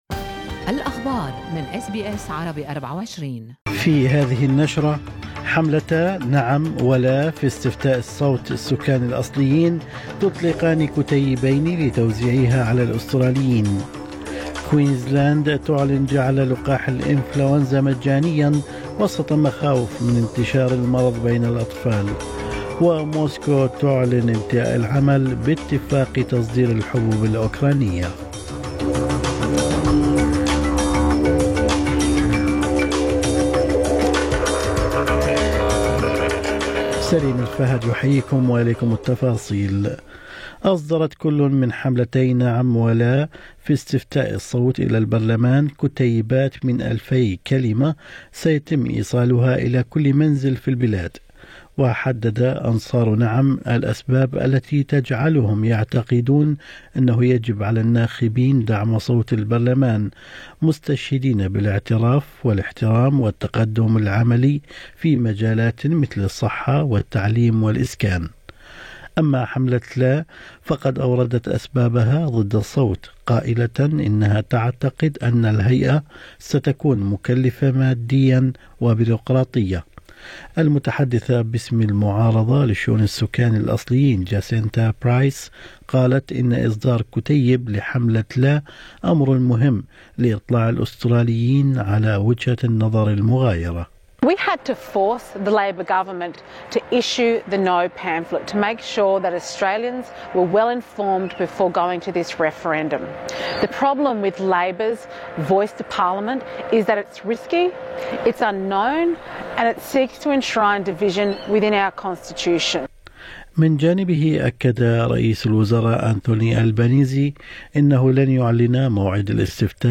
نشرة اخبار الصباح 18/7/2023